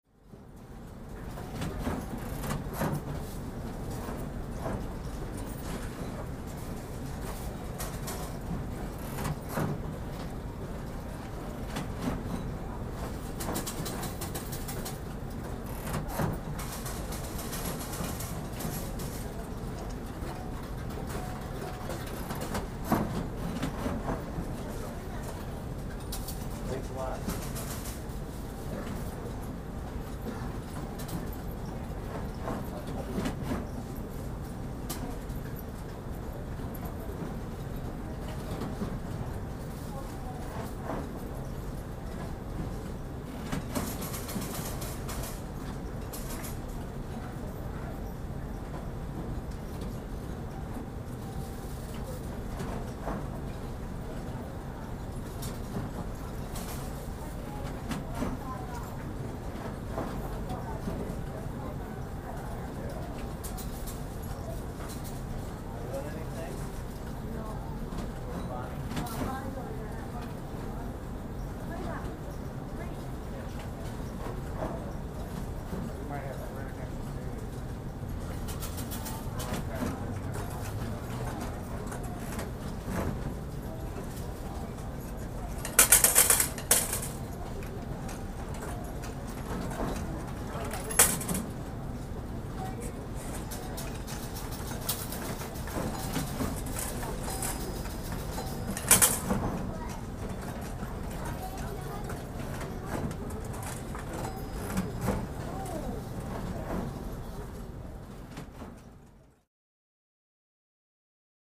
Casino Ambience, Near Slot Machines, W Light Walla.